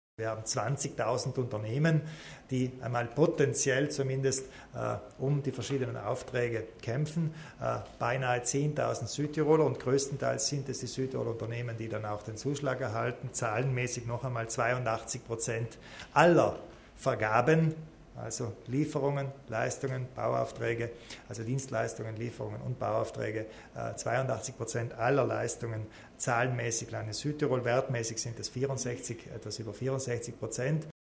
Landeshauptmann Kompatscher zu den Neuerungen beim Vergabegesetz